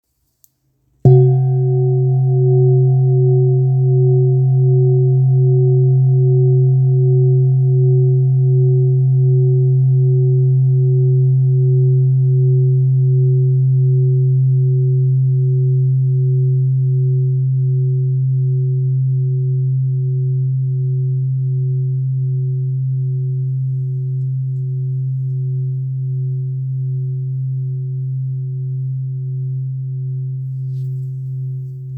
Kopre Singing Bowl, Buddhist Hand Beaten, Antique Finishing, 18 by 18 cm,
Material Seven Bronze Metal